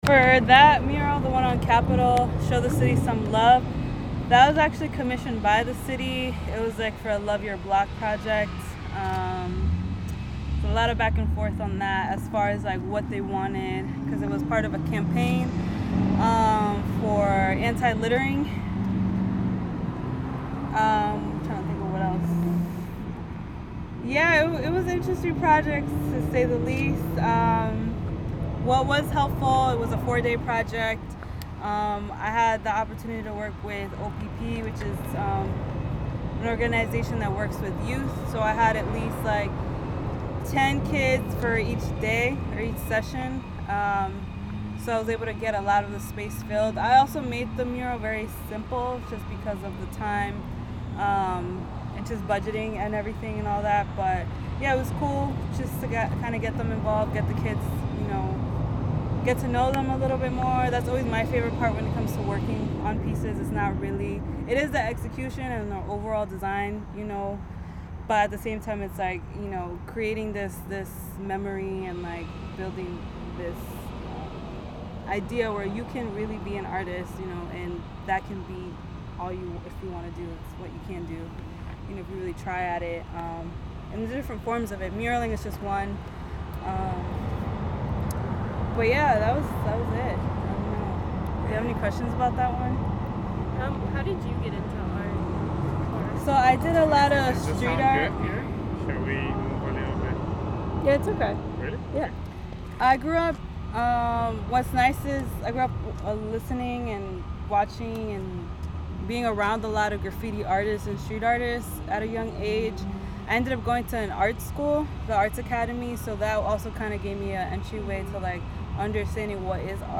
Show your City Some Love · Frog Hollow Oral History